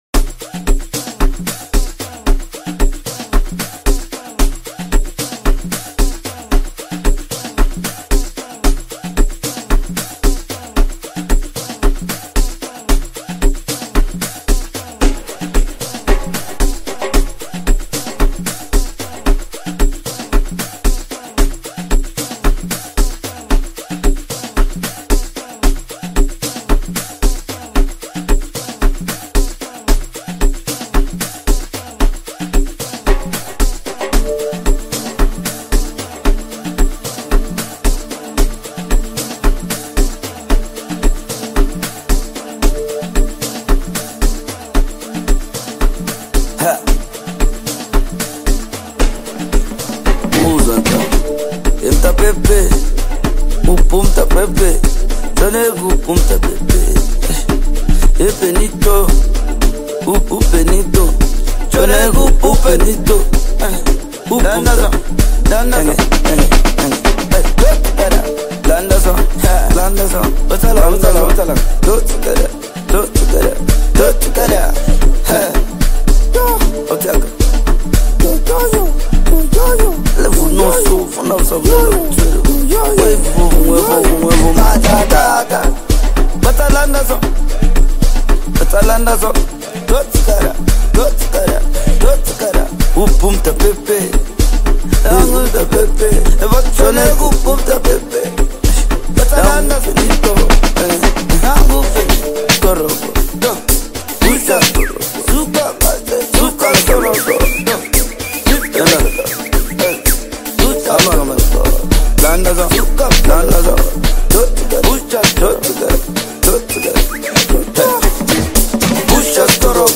Home » Amapiano » DJ Mix » Hip Hop